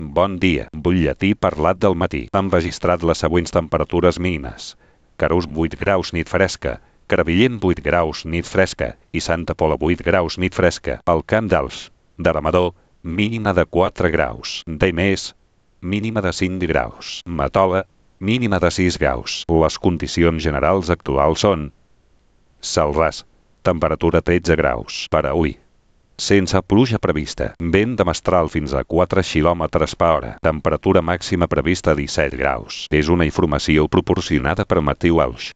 Butlletí parlat